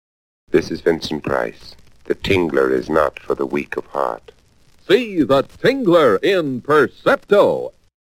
10, 20, 30, and 60 second radio spots
The-Tingler-Vincent-Price-10b-converted.mp3